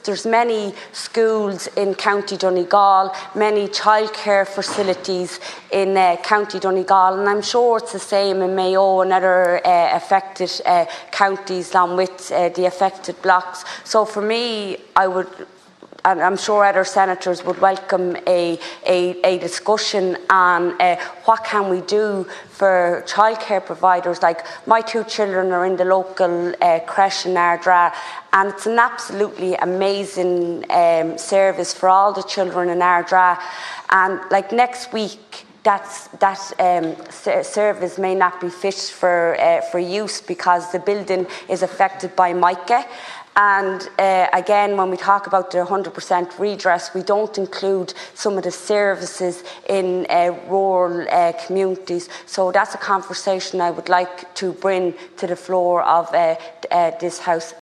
Donegal based Senator Eileen Flynn is calling for a discussion in the Seanad about the defective concrete crisis.